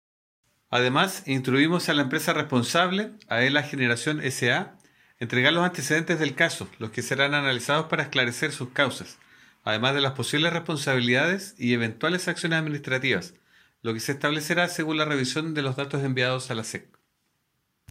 Audio: Manuel Cartagena, Director Regional de SEC Biobío